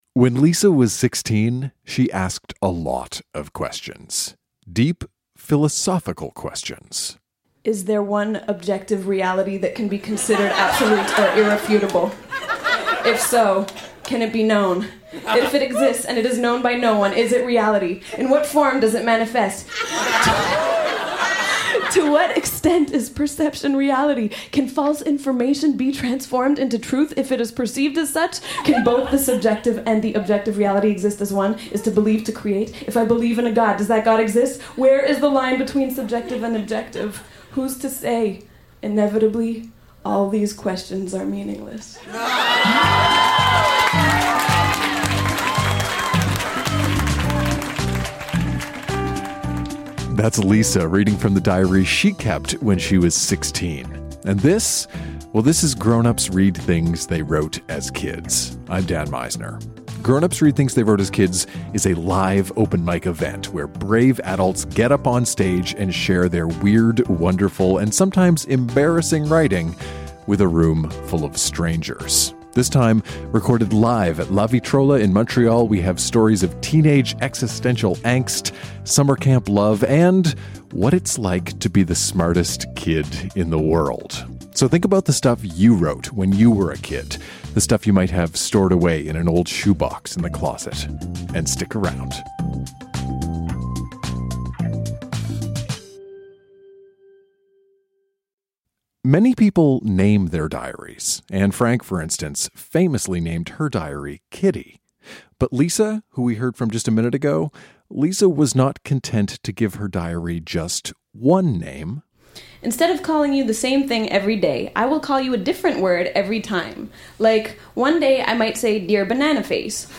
Season 2 kicks off with teenage existential angst, a visit with Ronald Reagan, and a few questions about Jesus. Recorded live at La Vitrola in Montreal, QC.